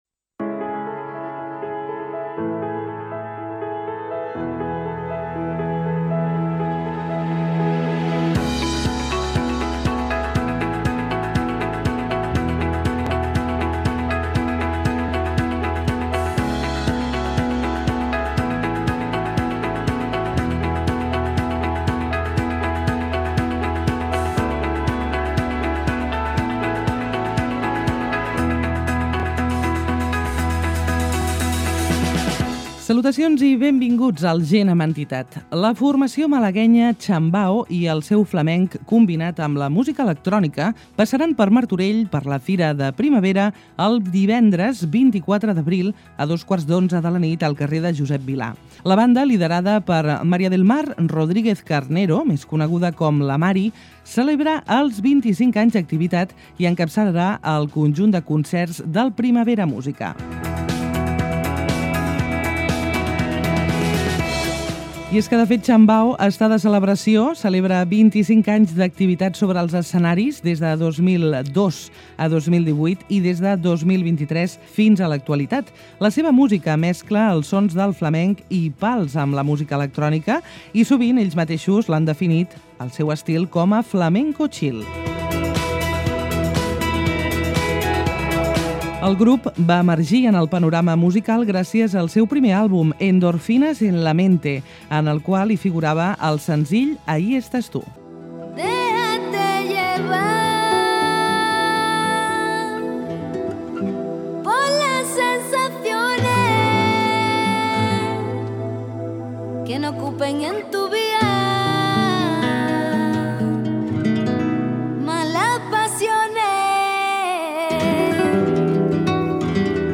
En aquesta entrevista